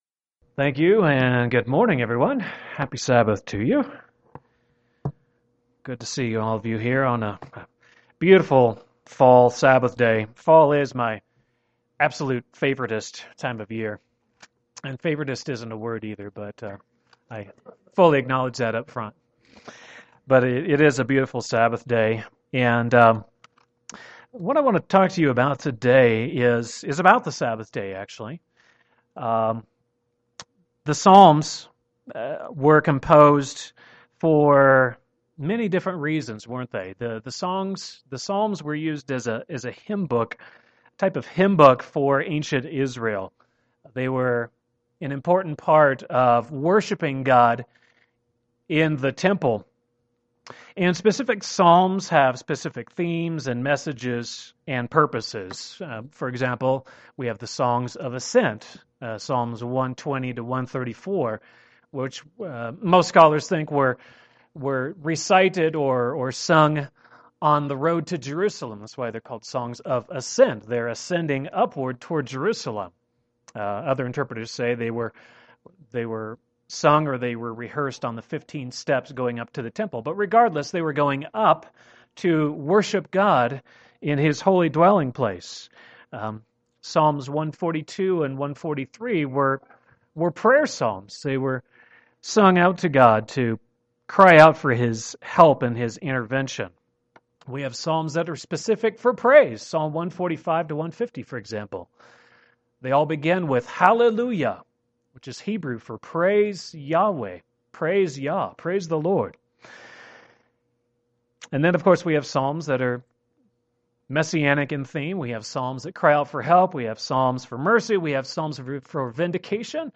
Sermons
Given in Denver, CO